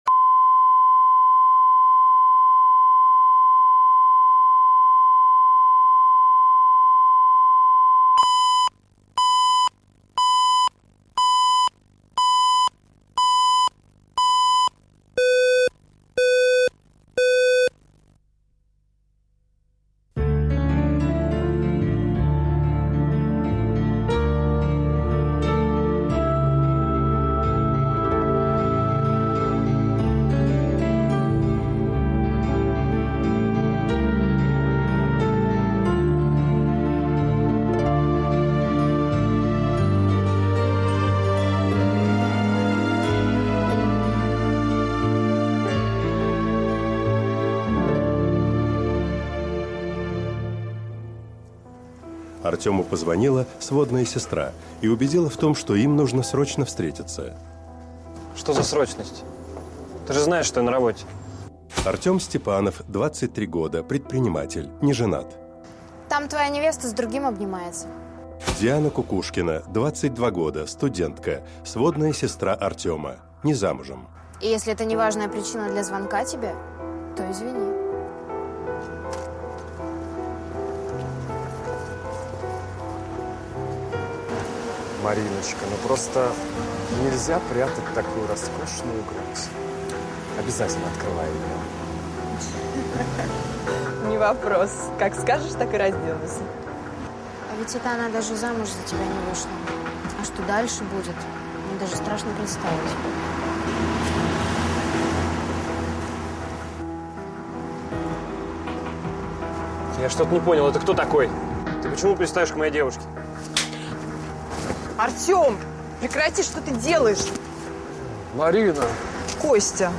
Аудиокнига Неотправленное письмо | Библиотека аудиокниг